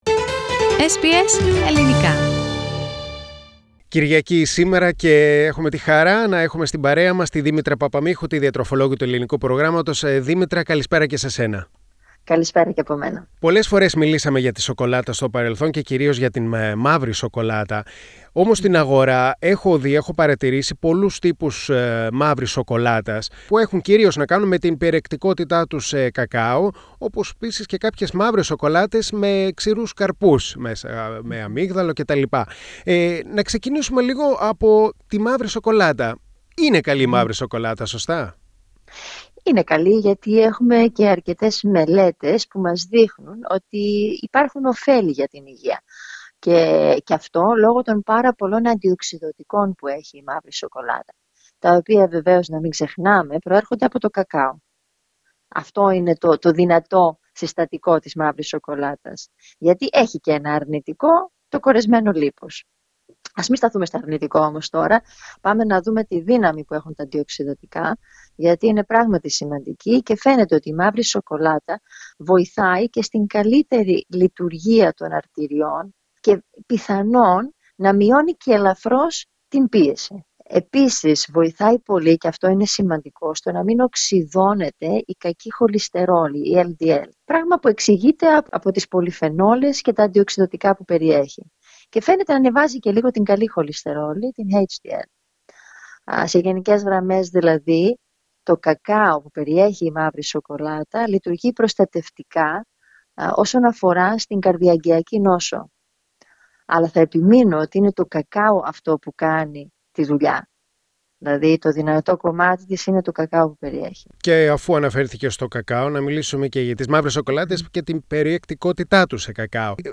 μιλώντας στο Ελληνικό Πρόγραμμα της Ραδιοφωνίας SBS.